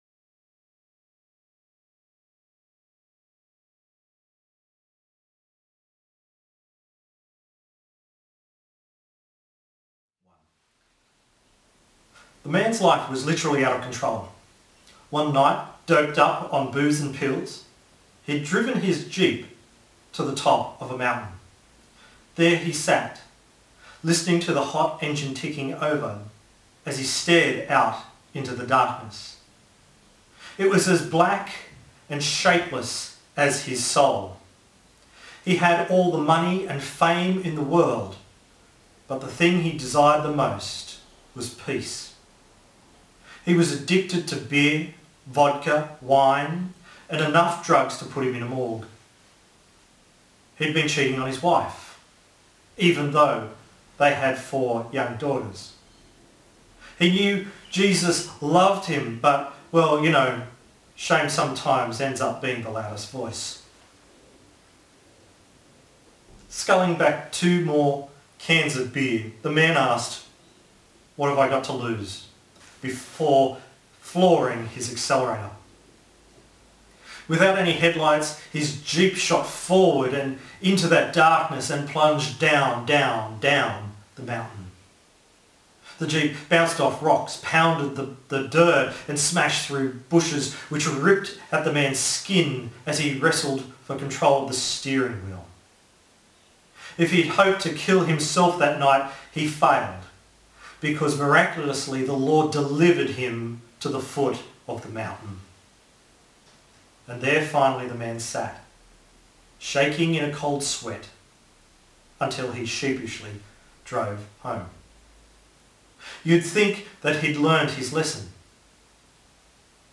Psalm 40:1-17 Service Type: Morning Service Topics